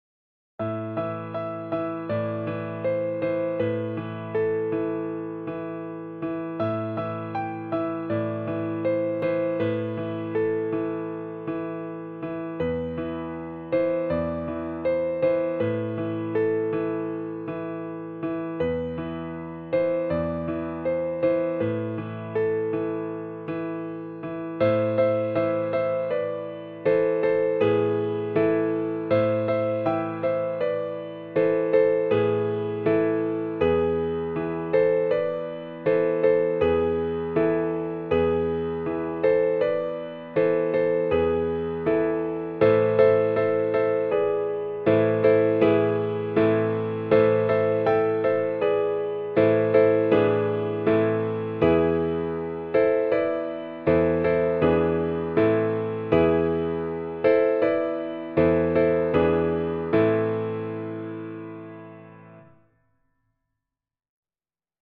a Russian Folk Song
for piano